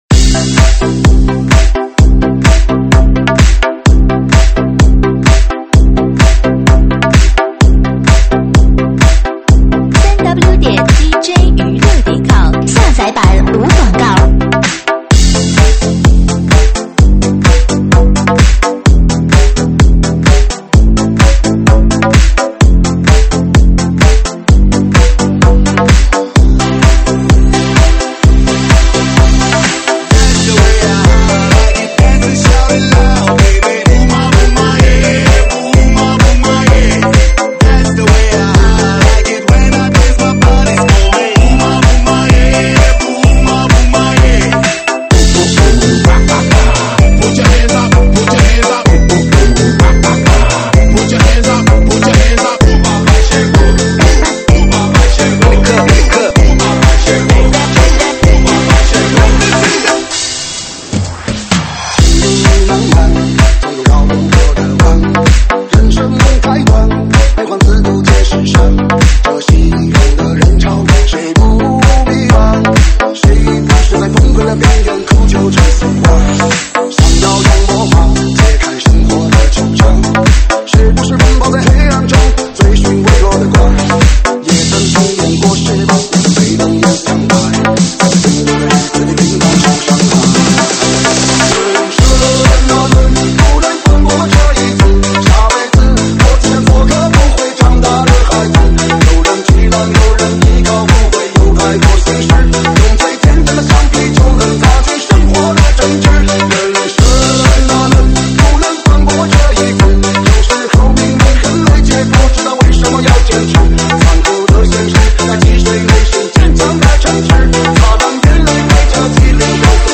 中文舞曲
舞曲类别：中文舞曲